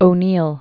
(ō-nēl), Eugene Gladstone 1888-1953.